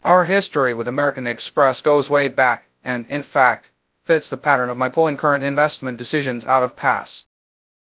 warren-buffett-speech